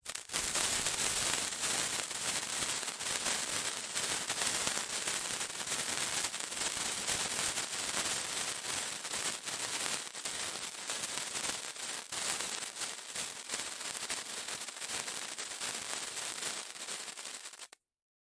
sparkler.mp3